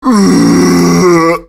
zombie_die_2.ogg